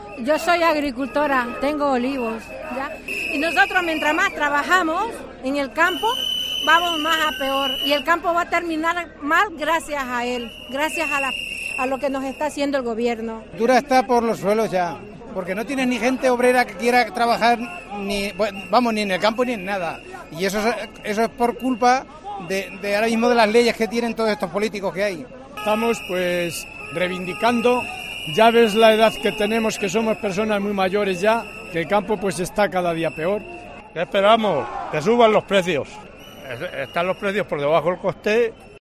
DECLARACIONES ORGANIZACIONES AGRARIAS